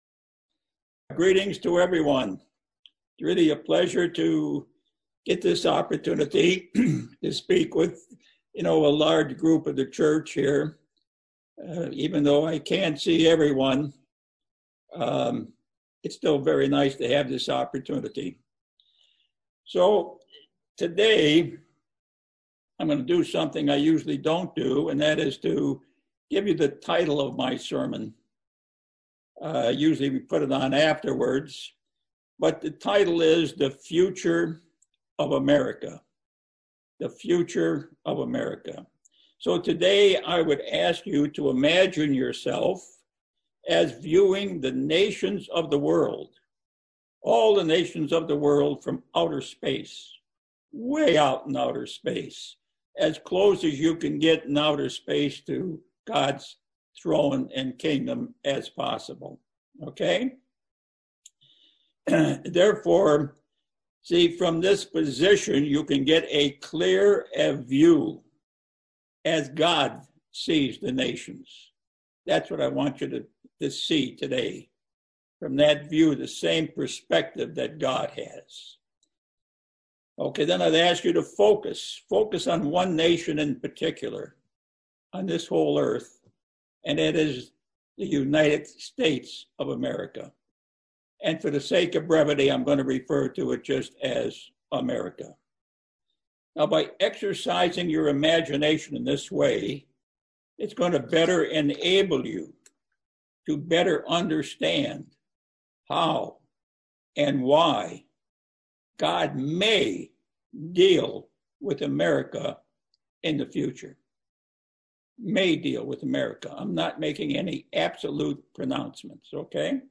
Given in Bakersfield, CA Los Angeles, CA